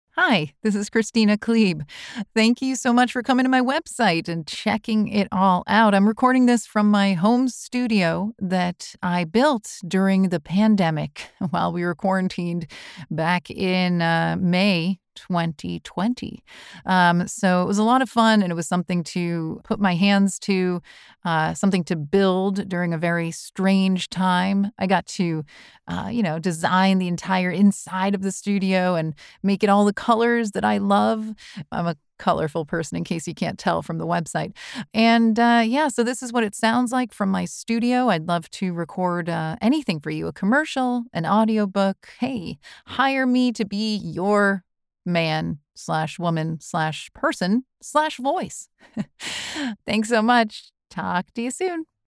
PROFESSIONAL HOME STUDIO:
Neumann TLM 103
The Sophisticated, International, Clear and Trustworthy Voice You’re Looking For
STUDIO SAMPLE
Studio-Sample-4-Website-Mastered.mp3